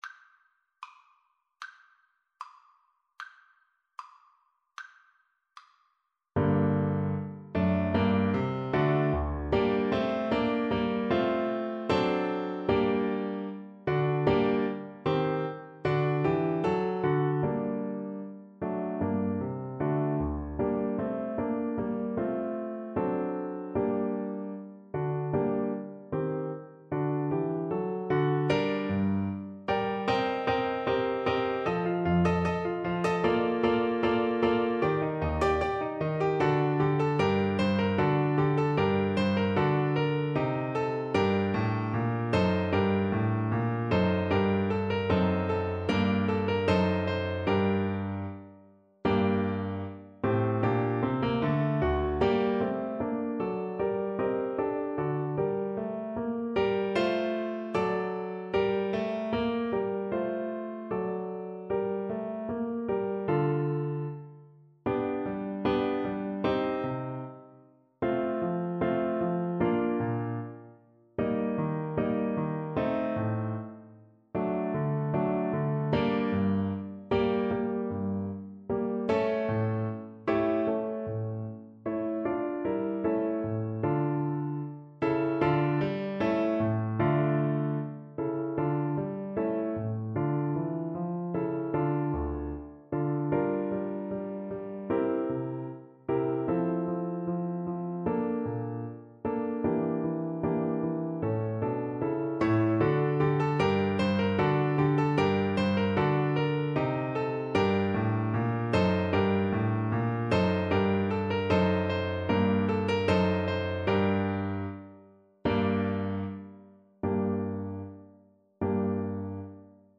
2/4 (View more 2/4 Music)
= 110 Presto (View more music marked Presto)
Classical (View more Classical Clarinet Music)